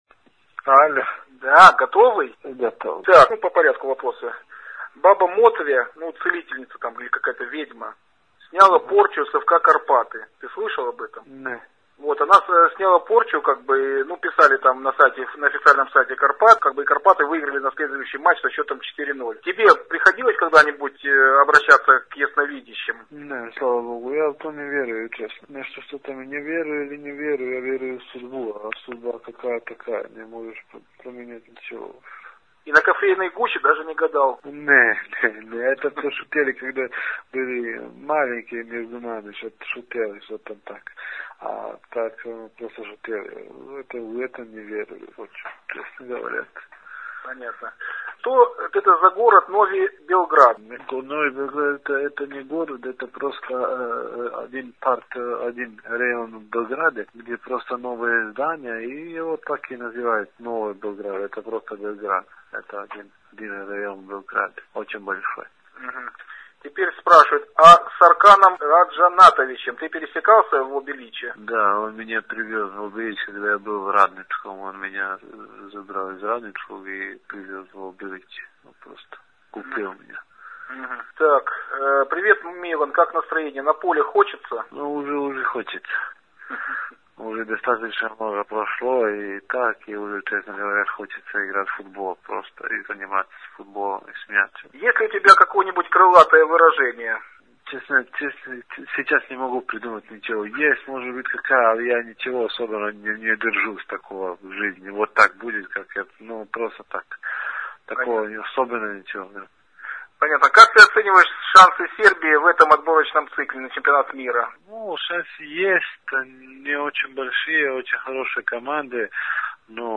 Телефонный разговор с Миланом Обрадовичем
Сегодня перед вылетом в Арабские Эмираты Милан Обрадович по телефону ответил на вопросы посетителей сайта. Смонтированные фрагменты этого разговора можно услышать, закачав звуковой файл по адресу: